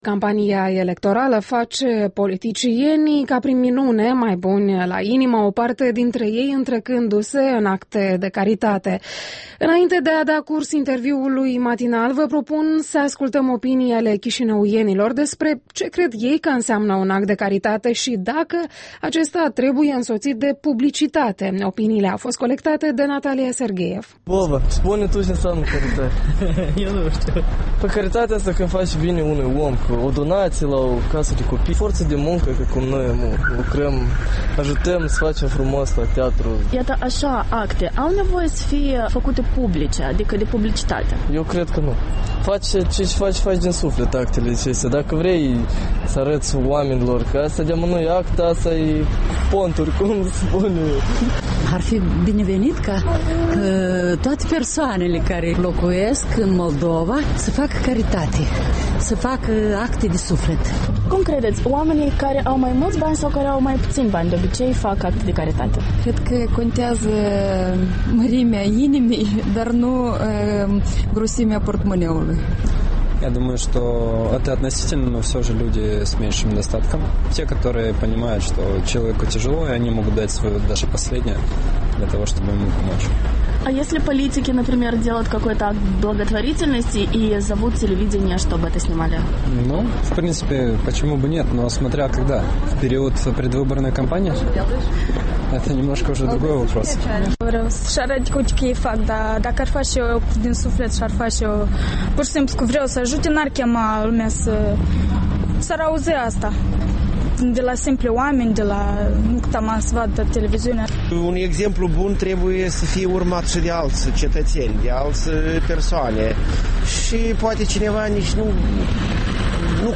Cu microfonul EL pe străzile Chișinăului